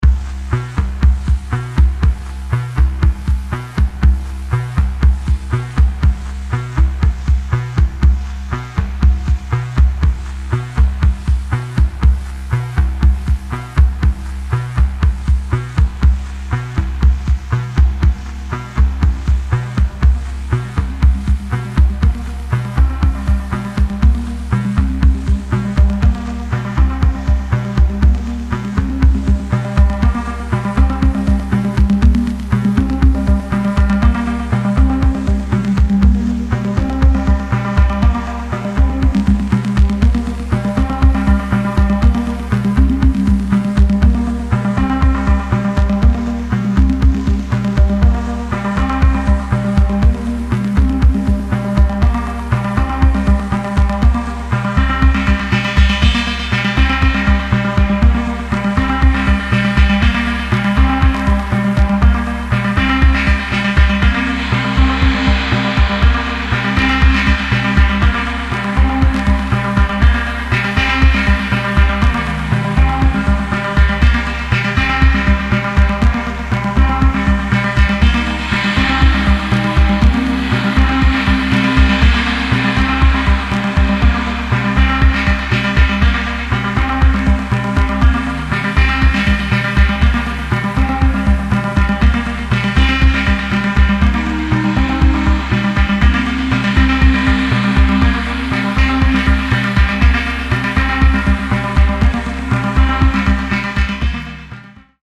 Nice range of Acid House and Techno tracks.